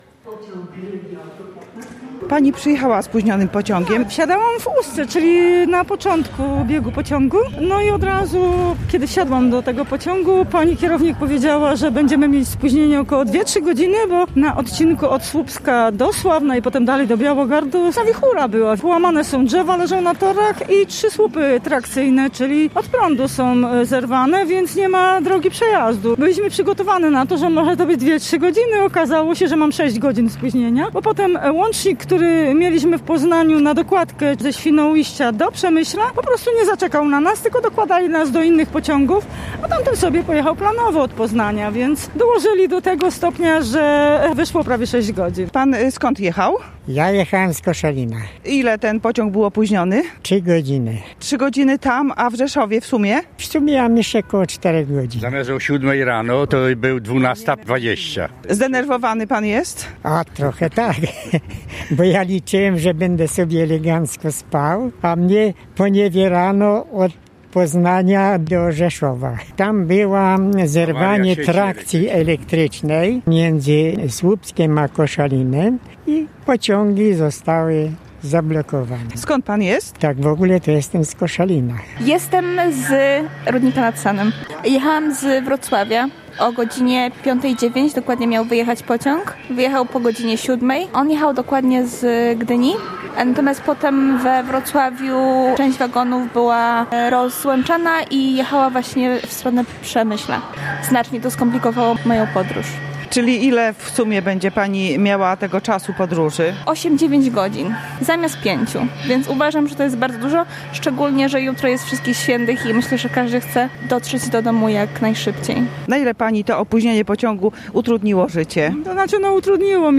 Z pasażerami, którzy po wielu godzinach dotarli do Rzeszowa, rozmawiała